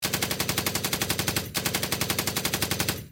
gun.mp3